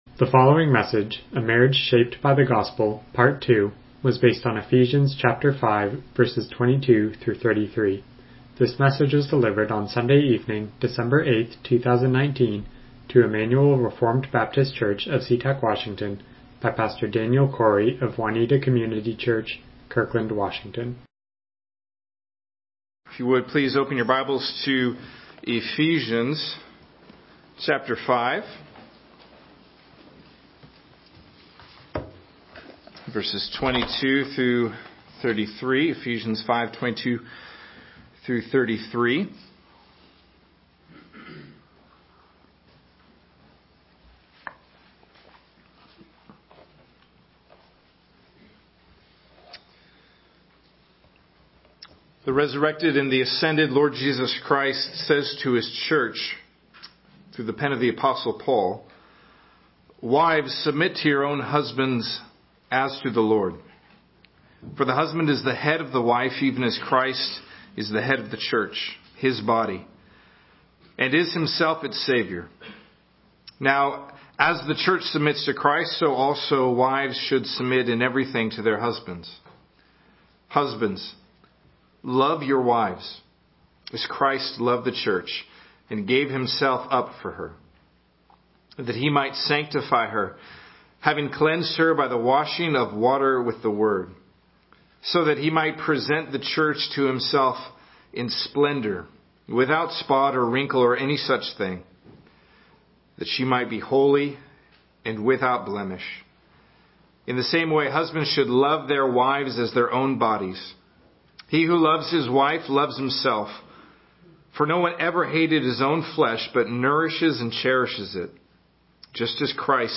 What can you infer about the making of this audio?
Passage: Ephesians 5:22-33 Service Type: Evening Worship